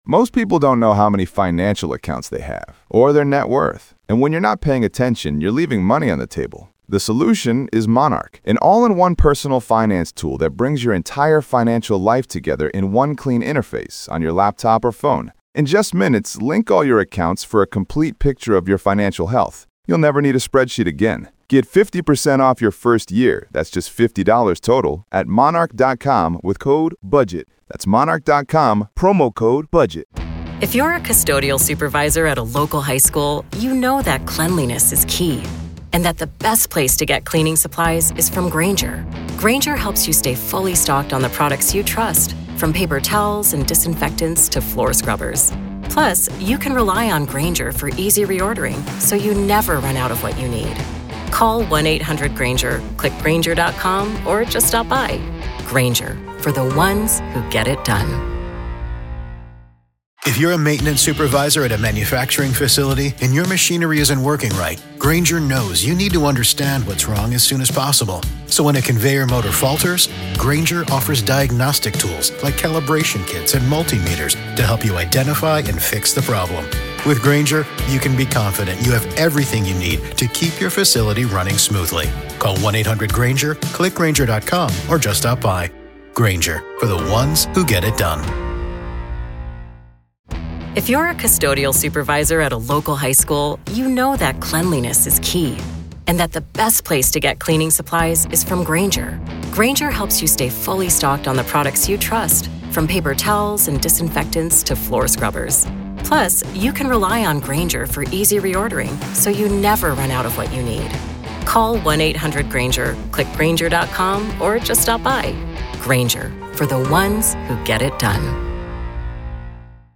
Each day’s proceedings bring new testimony, evidence, and revelations about what happened inside Richneck Elementary School on January 6, 2023 — and the administrative failures that followed. You’ll hear unfiltered courtroom audio, direct from the trial
Hidden Killers brings you the voices, the arguments, and the raw sound of justice in progress — as a jury decides whether silence and inaction inside a public school can rise to the level of legal accountability.